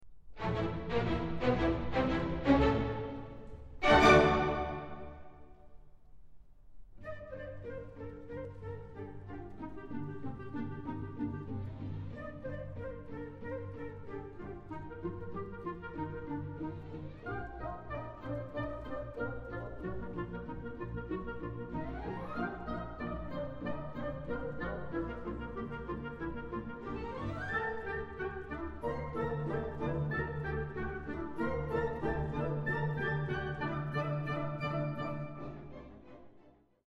Ballet Music